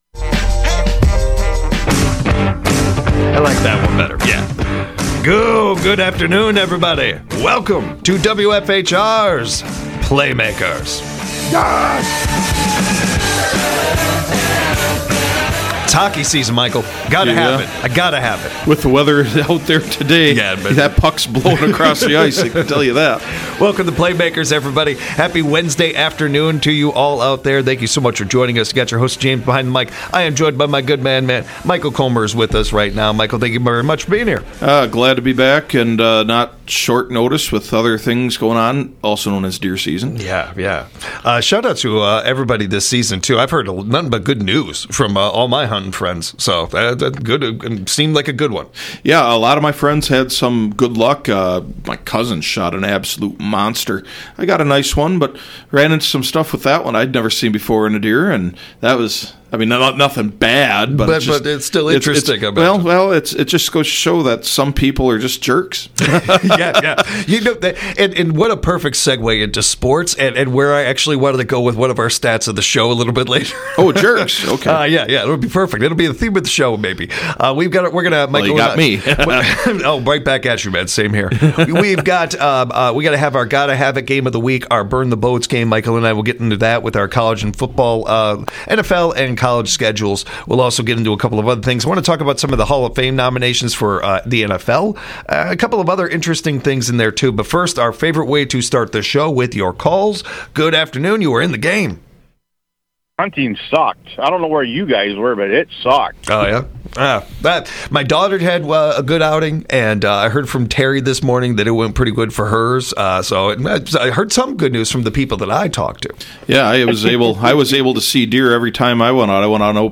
This show takes a deep dive into everything from local to world wide sports. With local sports guests and call-ins from the audience, this show is a highlight every Monday, Wednesday, Friday from 5pm - 6pm on WFHR.